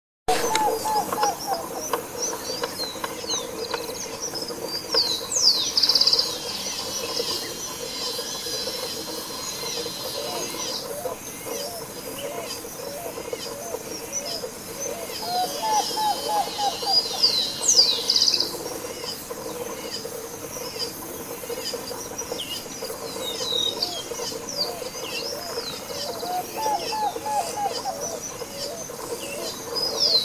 Dark-billed Cuckoo (Coccyzus melacoryphus)
Life Stage: Adult
Location or protected area: Reserva Natural del Pilar
Condition: Wild
Certainty: Photographed, Recorded vocal
cuclillo-canela.mp3